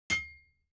HammerHit02.wav